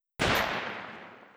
AR3_ShootTail 01.wav